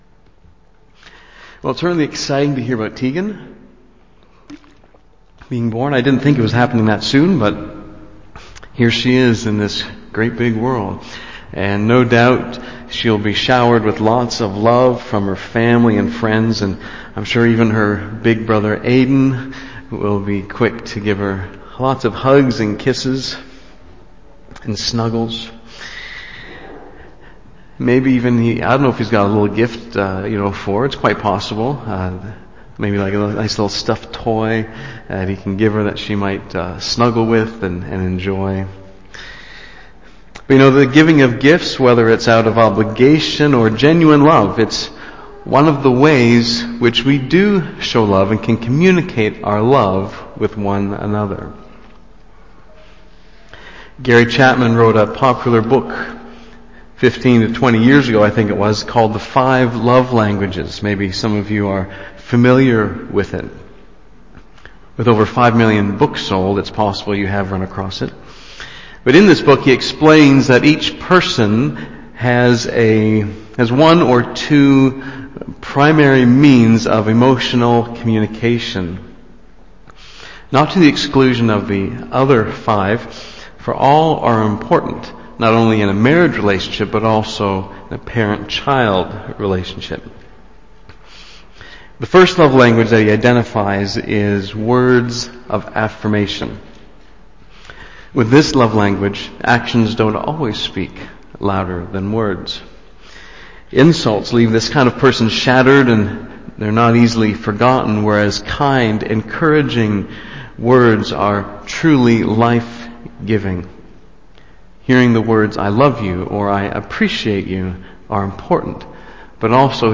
2013 The Wealth of God’s Love and Grace BACK TO SERMON LIST Preacher